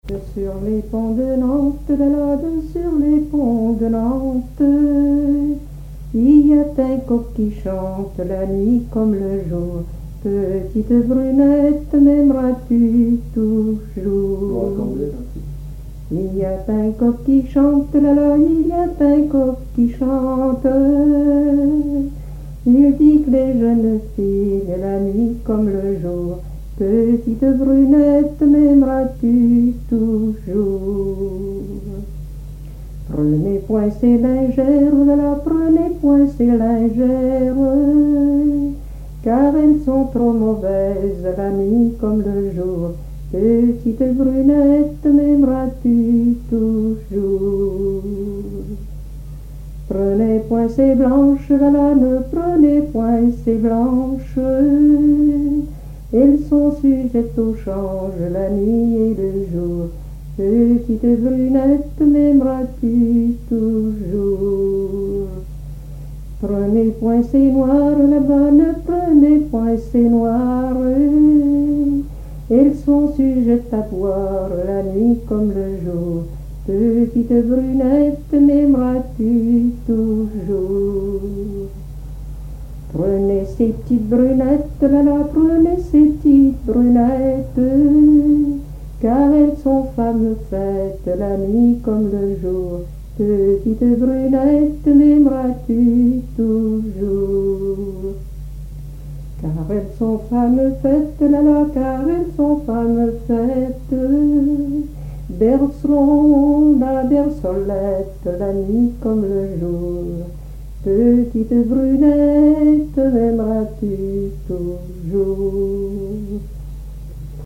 danse : ronde : grand'danse
Genre laisse
Pièce musicale inédite